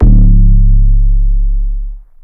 808 (special).wav